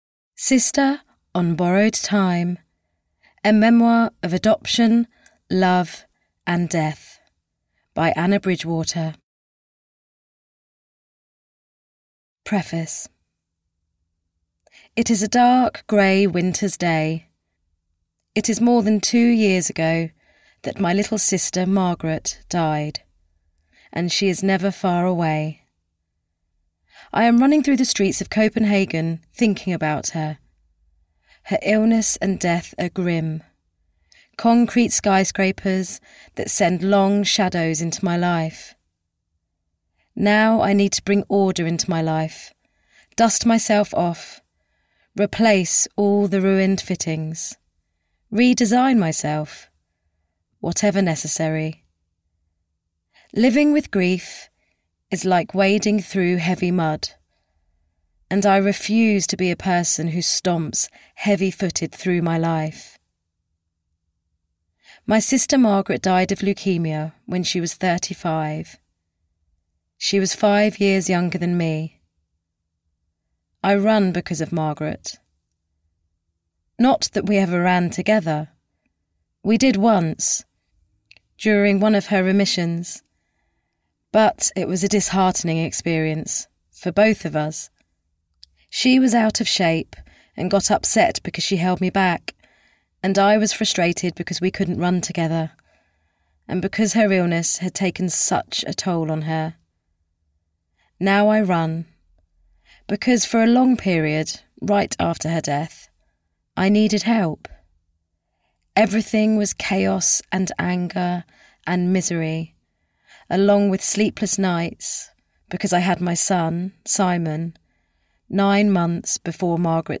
Sister on Borrowed Time (EN) audiokniha
Ukázka z knihy